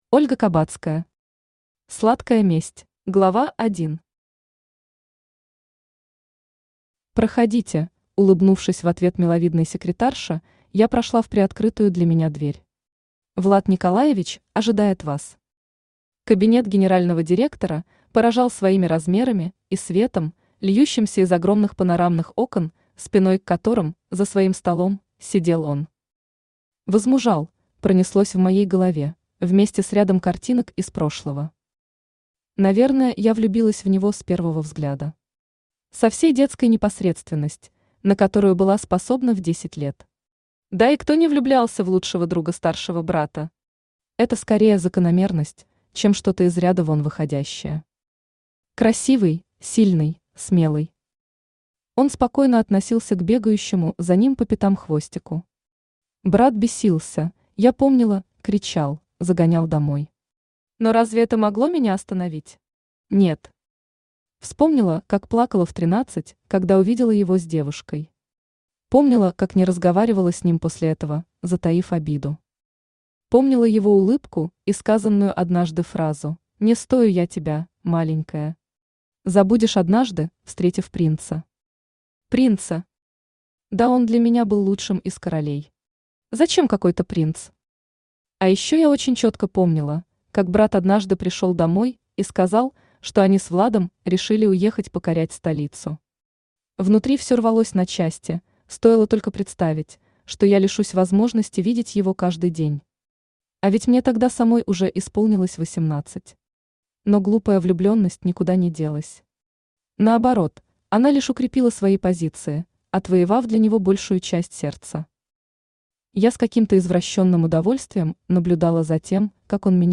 Аудиокнига Сладкая месть | Библиотека аудиокниг
Aудиокнига Сладкая месть Автор Ольга Кабацкая Читает аудиокнигу Авточтец ЛитРес.